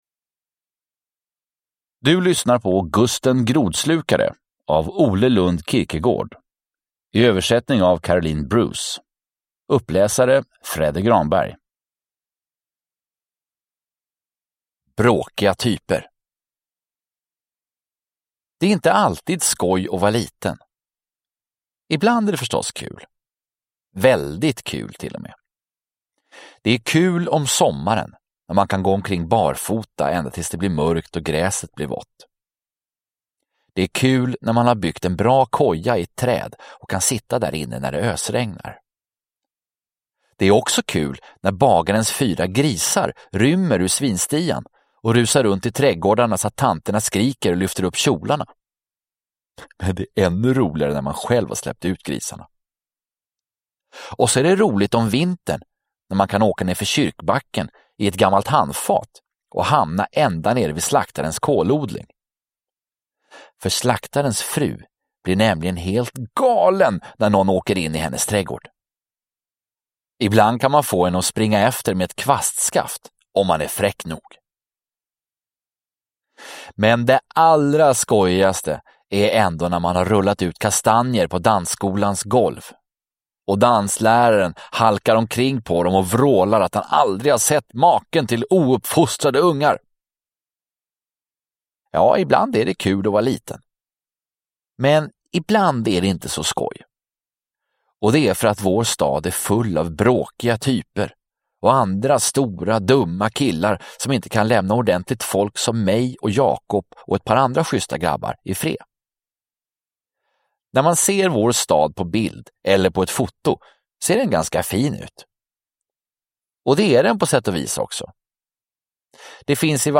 Gusten Grodslukare – Ljudbok – Laddas ner